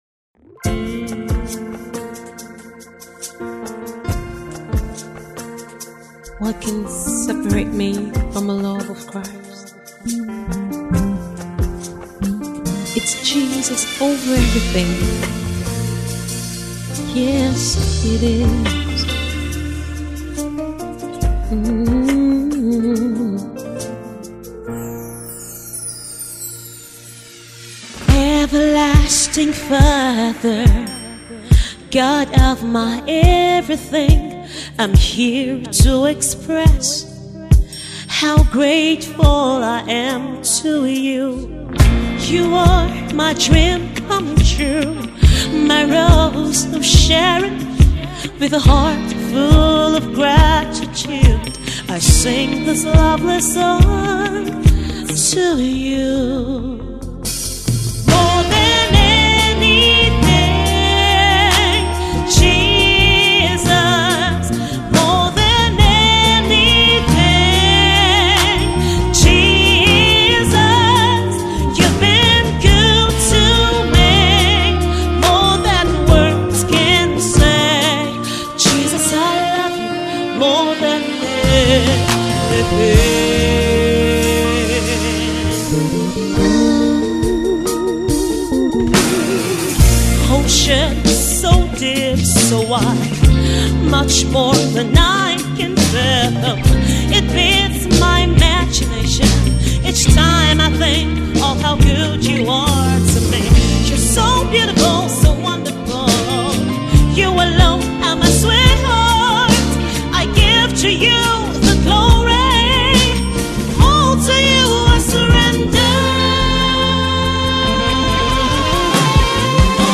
It depicts the depth of the singer’s emotions.